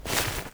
glider_open.wav